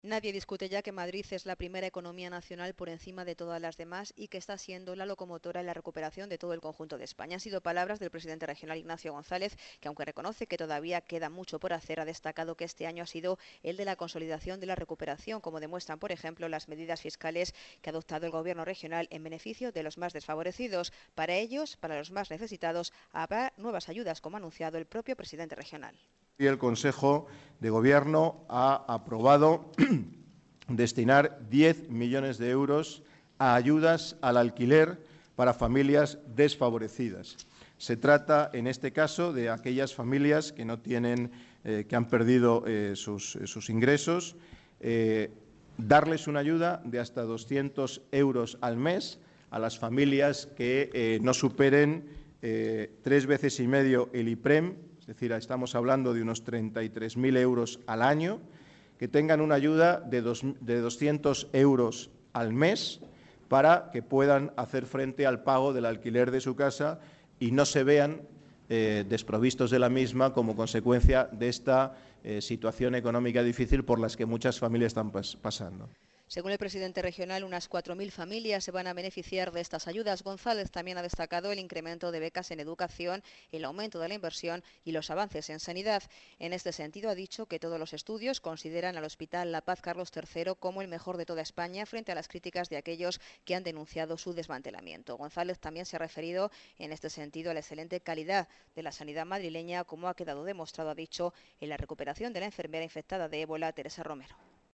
"Por primera vez nadie discute que en este año 2014, Madrid es la primera economía nacional", ha aseverado el presidente en rueda de prensa posterior al Consejo de Gobierno, donde ha estado arropado por todo su equipo de Gobierno.